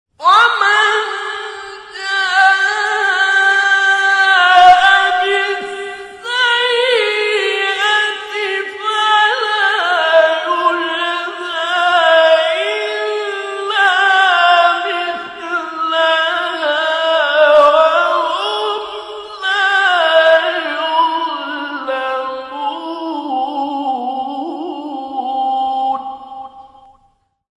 گروه فعالیت‌های قرآنی: مقاطع زیبا و شنیدی از قاریان مصری و ایرانی که در کانال‌ها و گروه‌های قرآنی تلگرام منتشر شده است، ارائه می‌شود.
مقام سه‌گاه